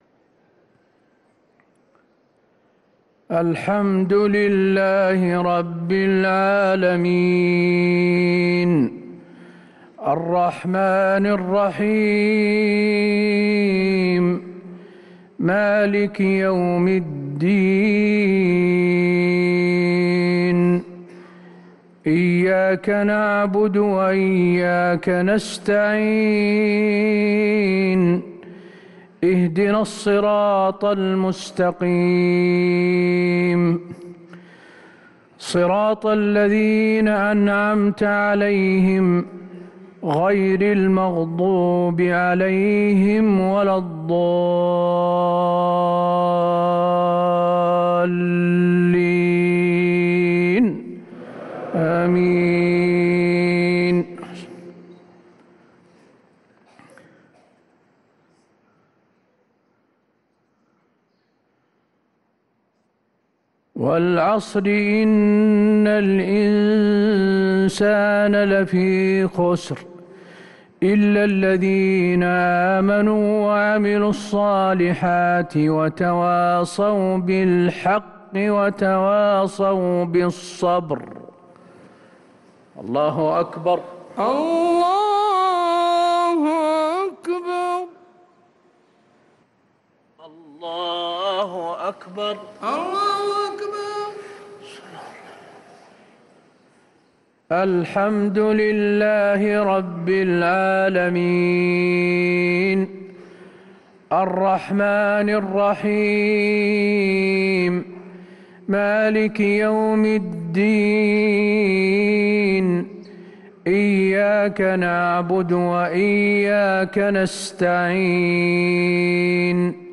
صلاة المغرب للقارئ حسين آل الشيخ 1 جمادي الآخر 1445 هـ
تِلَاوَات الْحَرَمَيْن .